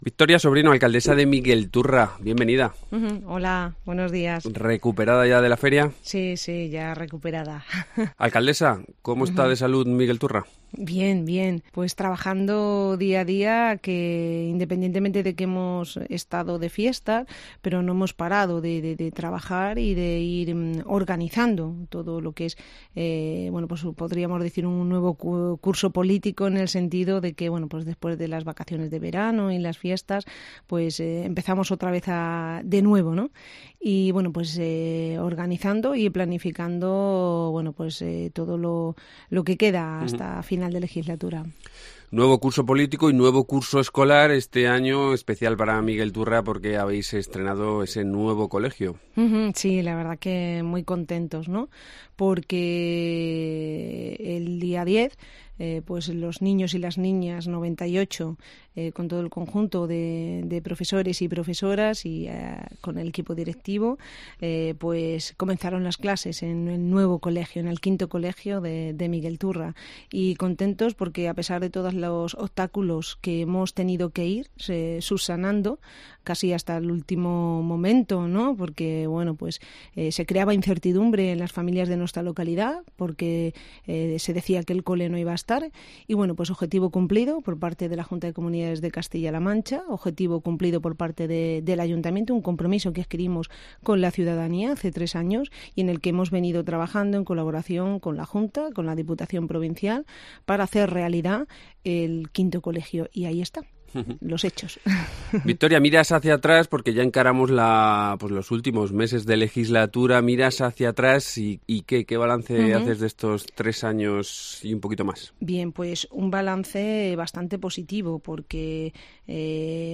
Redacción digital Madrid - Publicado el 19 sep 2018, 12:07 - Actualizado 15 mar 2023, 15:05 1 min lectura Descargar Facebook Twitter Whatsapp Telegram Enviar por email Copiar enlace Miguelturra acaba de celebrar sus fiestas y encara un nuevo curso con muchos proyectos en cartera. Hoy está con nosotros su alcaldesa, Victoria Sobrino.